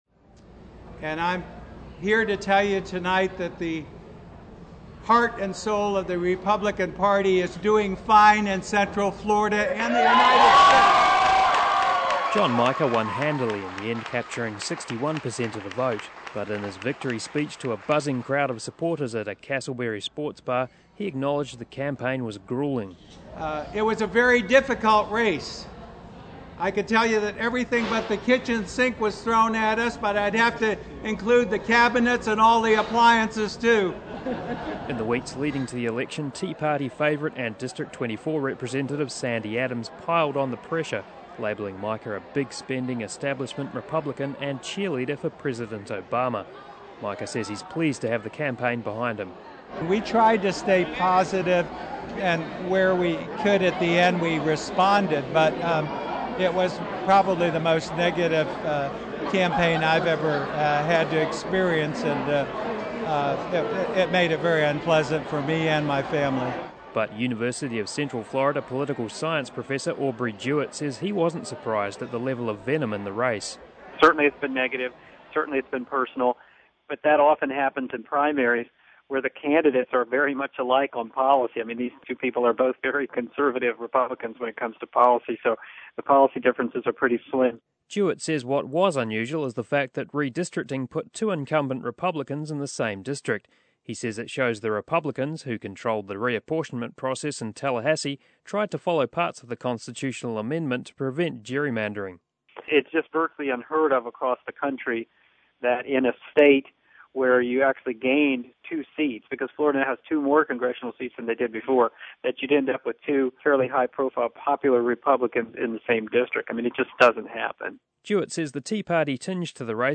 John Mica won handily in the end, capturing 61 per cent of the vote, but in his victory speech to a buzzing crowd of supporters at a Casselberry Sports bar, he acknowledged the campaign was grueling.
District 7 election night .mp3